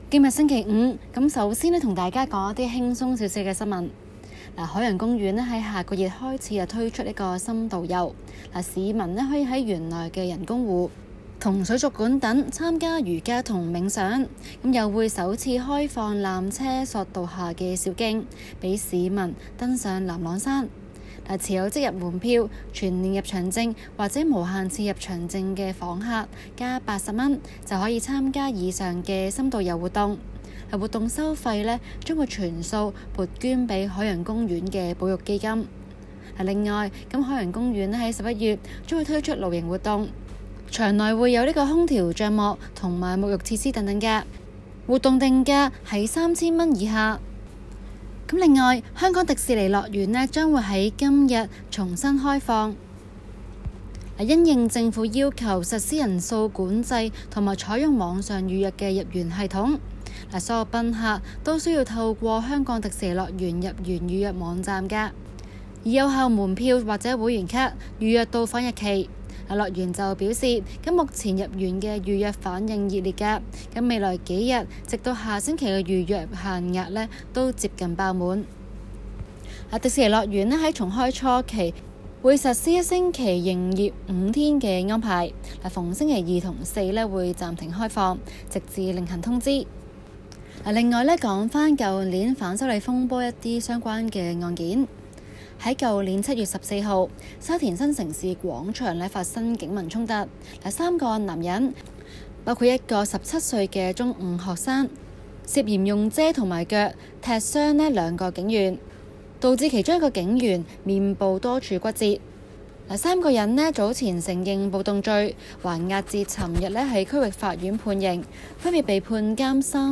今期【中港快訊】報導香港新聞界反對警方修訂記者定義，要求撤回並保留法律行動。